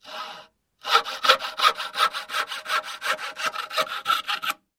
Звуки лобзика
Лобзик - Альтернативный вариант